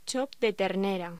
Locución: Chop de ternera